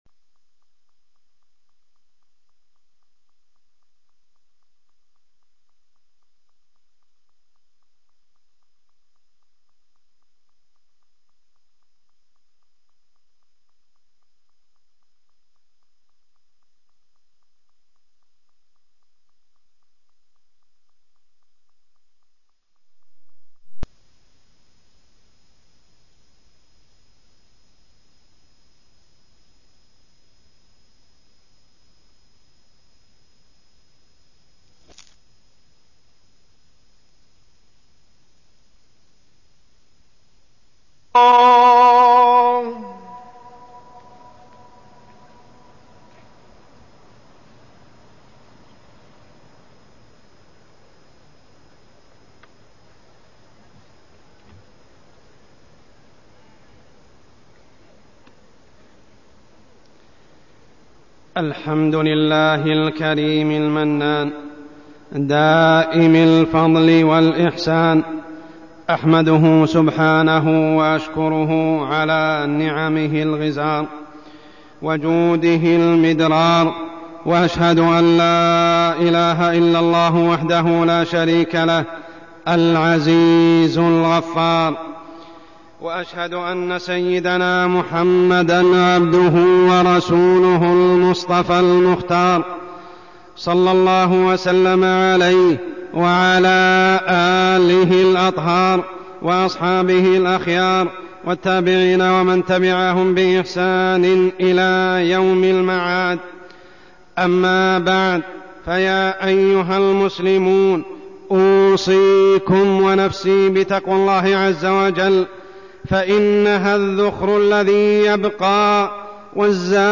تاريخ النشر ٨ ربيع الثاني ١٤١٩ هـ المكان: المسجد الحرام الشيخ: عمر السبيل عمر السبيل الأسرة